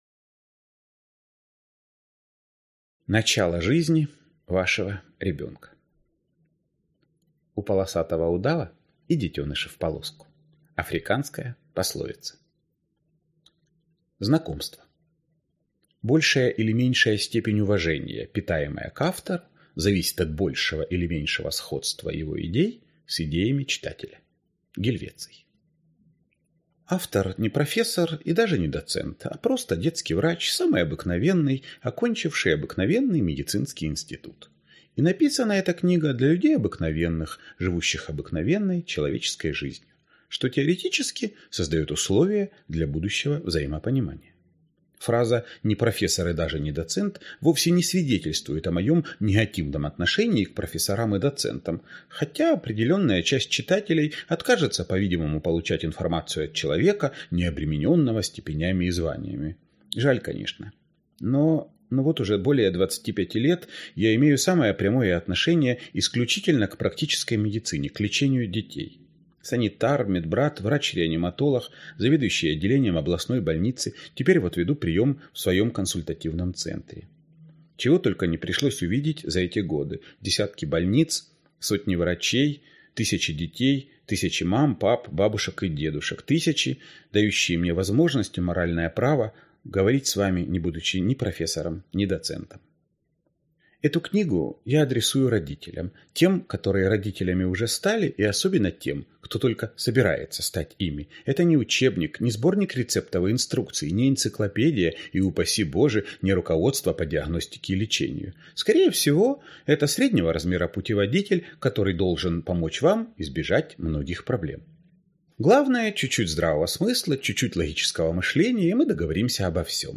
Аудиокнига Начало жизни. Ваш ребенок от рождения до года | Библиотека аудиокниг
Ваш ребенок от рождения до года Автор Евгений Комаровский Читает аудиокнигу Евгений Комаровский.